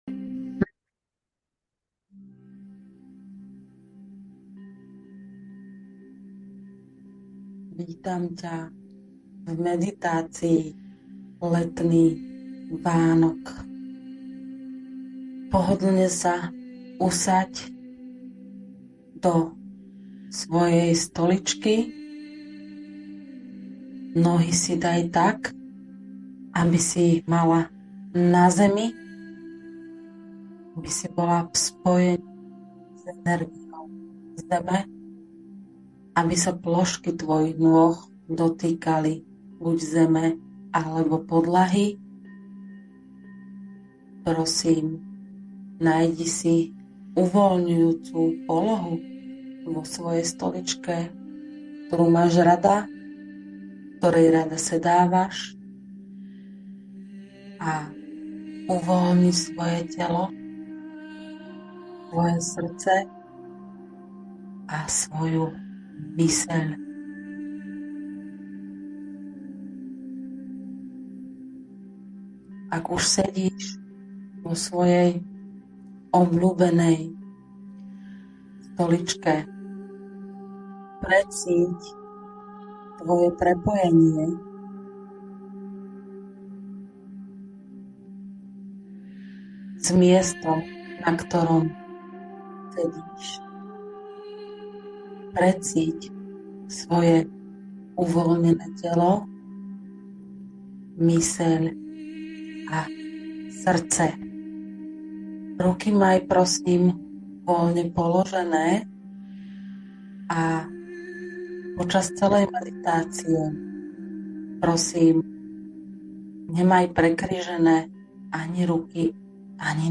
Meditácia Letný Vánok